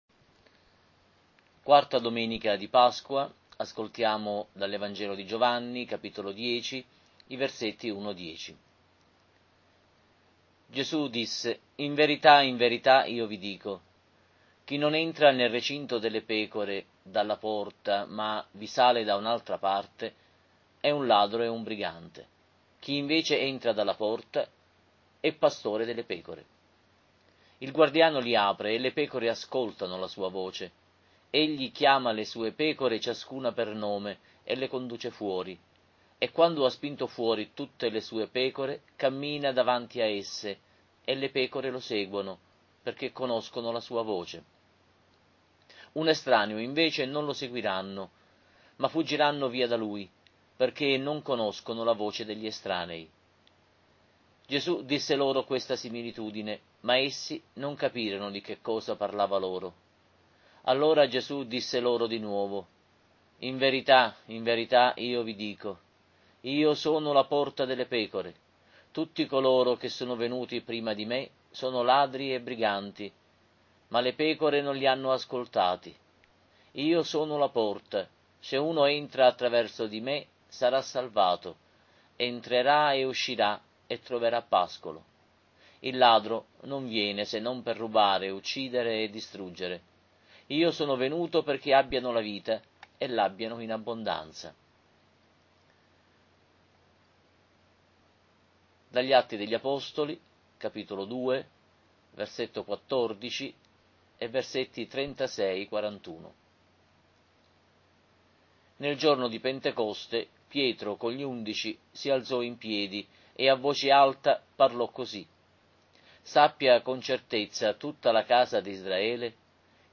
Lectio divina Domenica «DEL BUON PASTORE», IV Dom. di Pasqua A